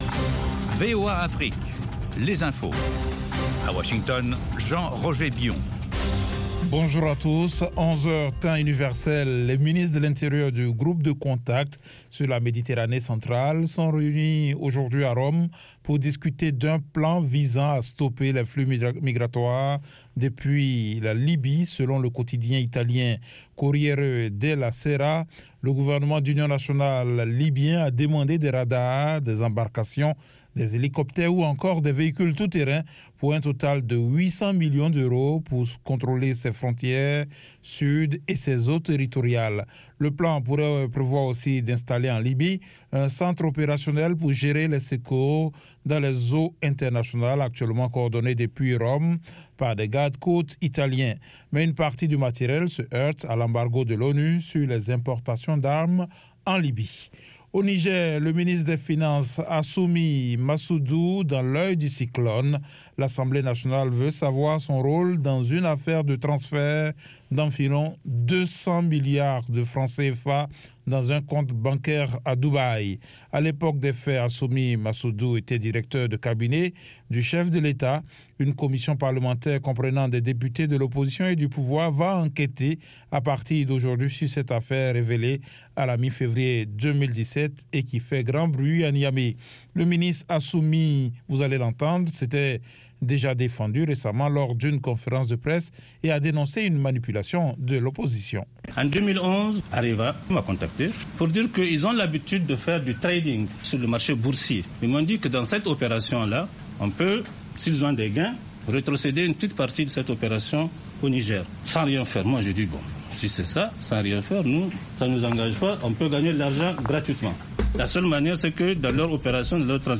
10 min Newscast